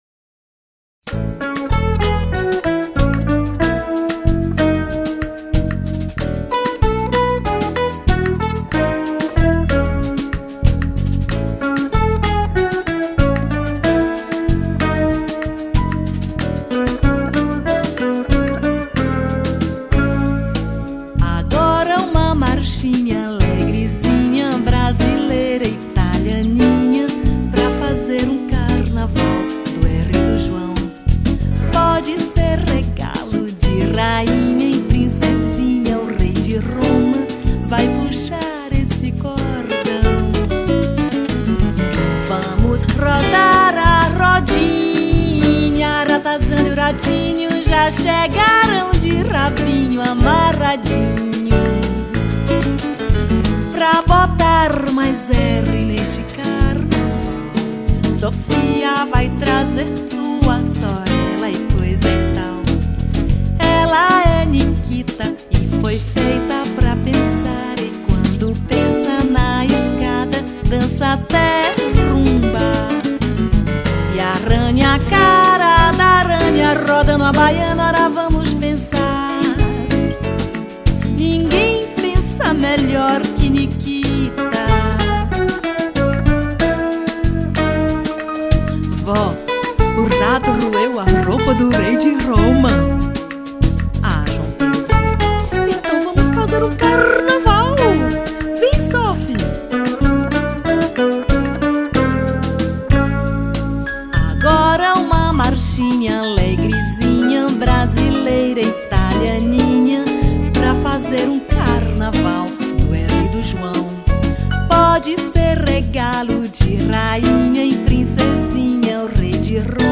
1578   03:30:00   Faixa:     Bossa nova
Baixo Elétrico 6
Bateria
Guitarra, Violao Acústico 6
Teclados